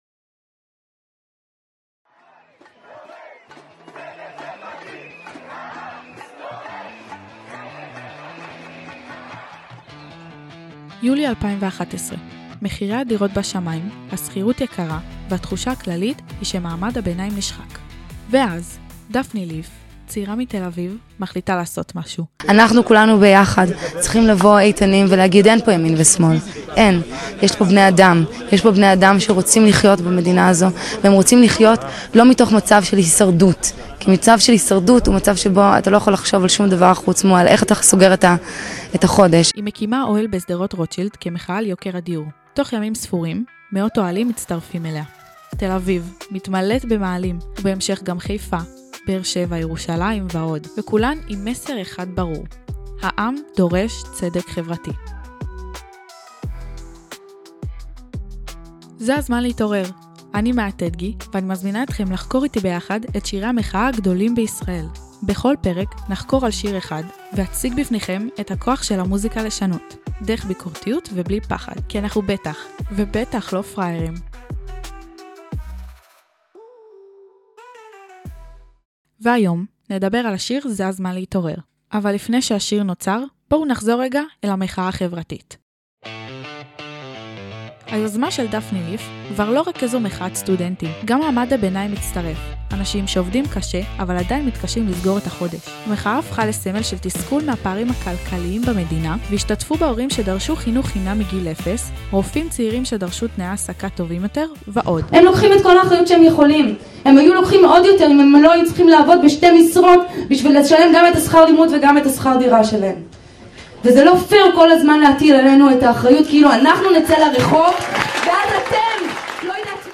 ננסה להבין בריאיון